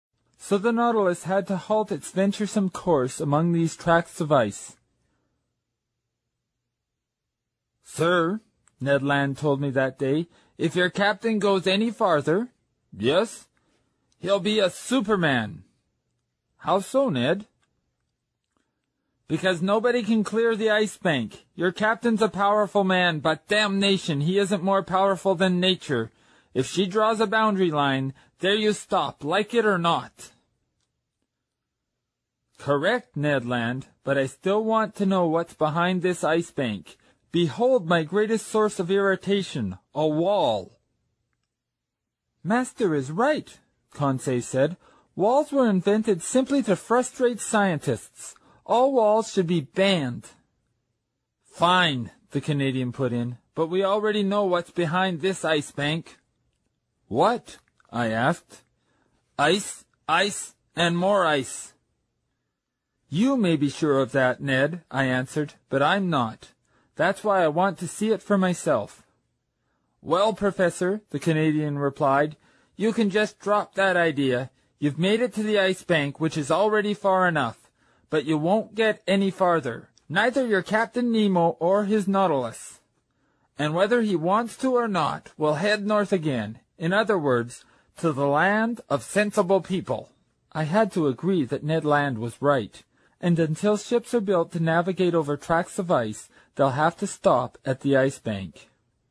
在线英语听力室英语听书《海底两万里》第433期 第26章 大头鲸和长须鲸(14)的听力文件下载,《海底两万里》中英双语有声读物附MP3下载